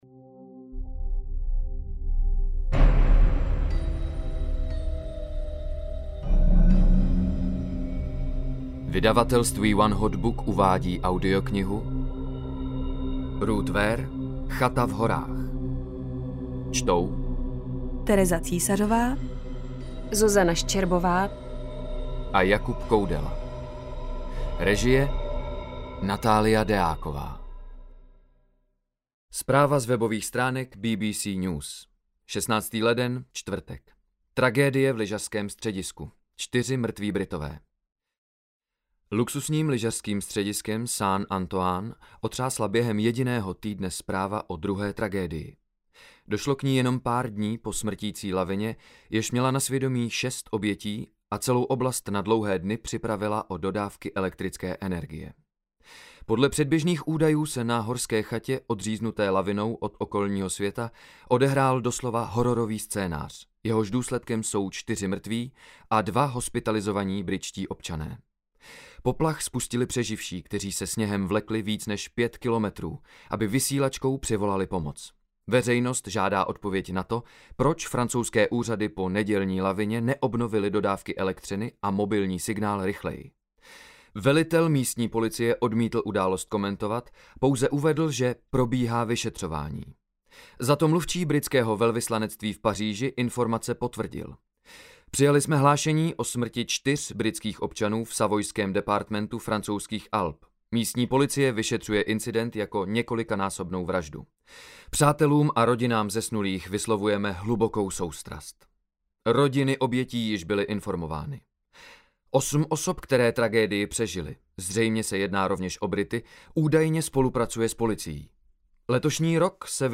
Chata v horách audiokniha
Ukázka z knihy
chata-v-horach-audiokniha